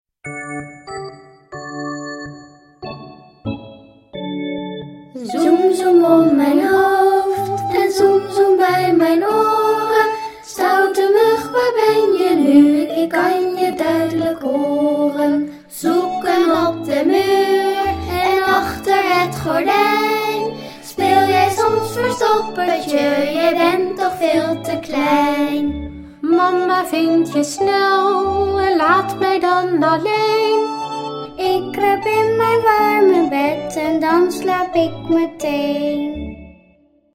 liedjes voor peuters en kleuters